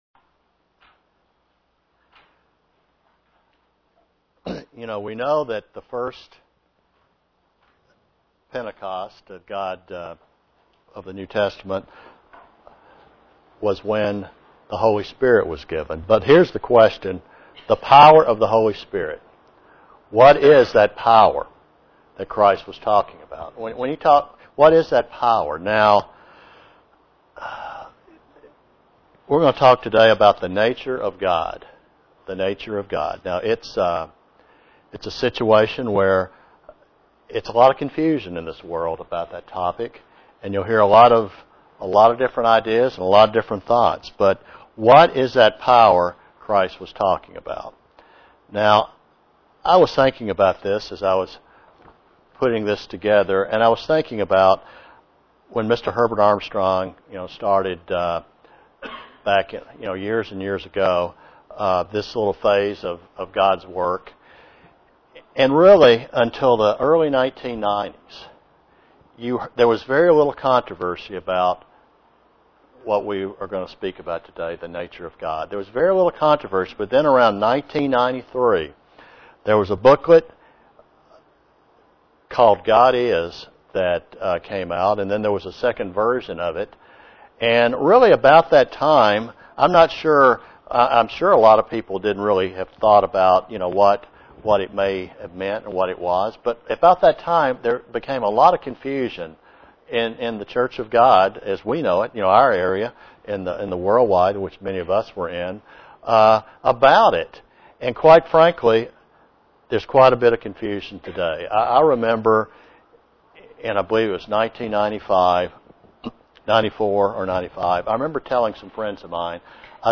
The Nature of God (Presented to the Knoxville TN, Church)
Sermons